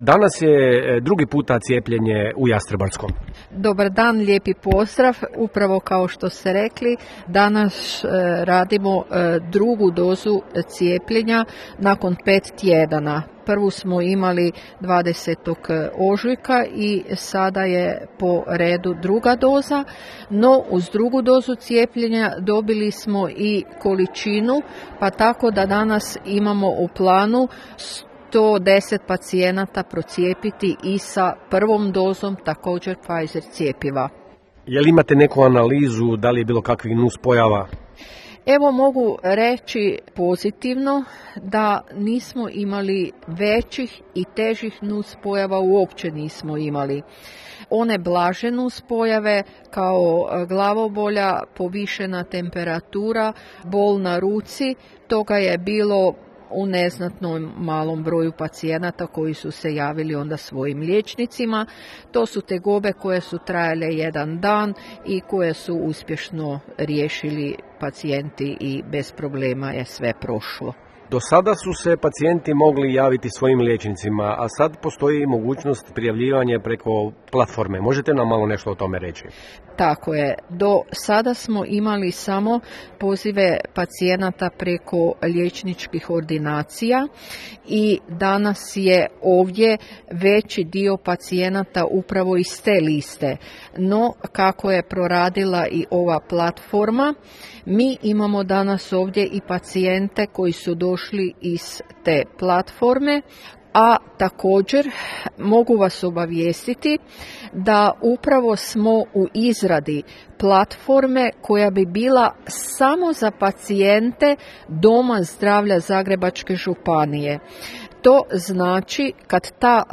Naš novinar razgovarao je, kao i prilikom prvog cijepljenja,